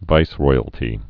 (vīsroiəl-tē, vīs-roi-)